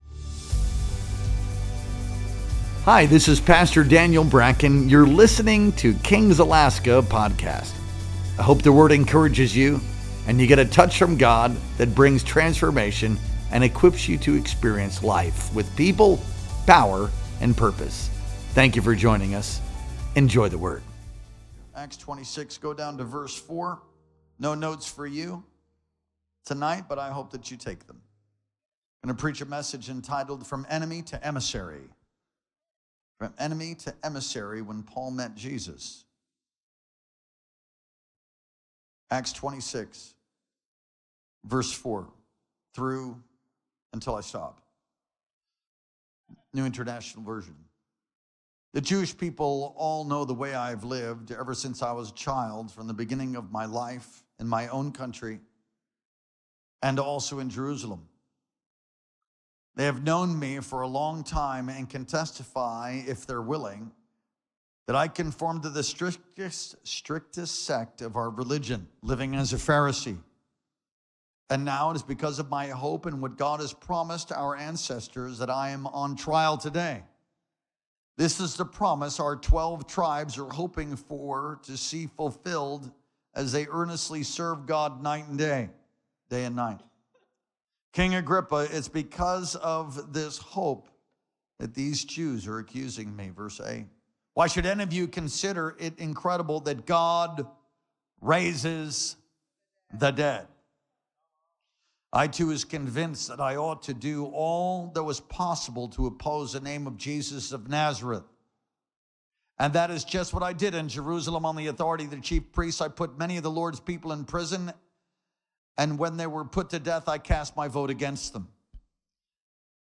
Our Sunday Night Worship Experience streamed live on April 20th, 2025.